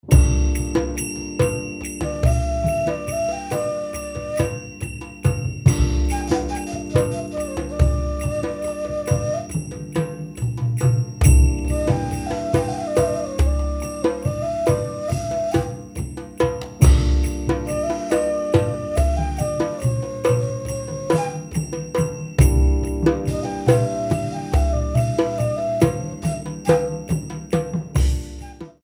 thirteen beats